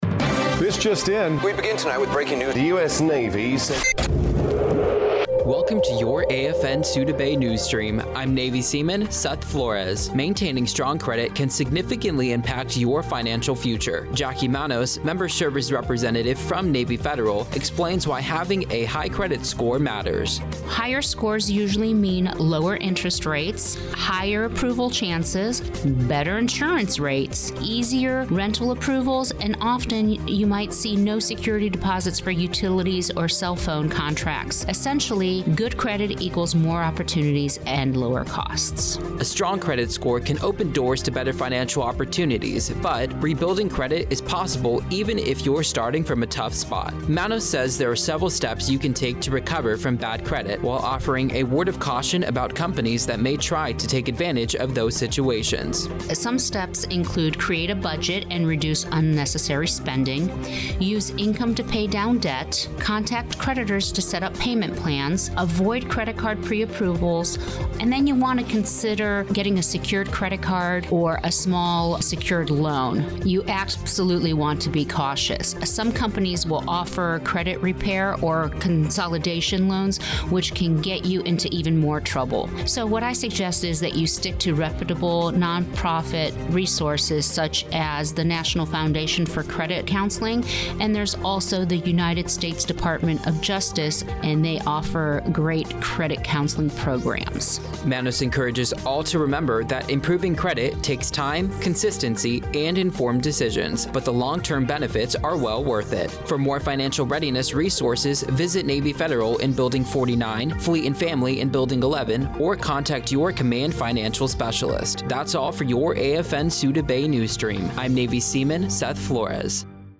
260205 NSASOUDABAYRADIONEWS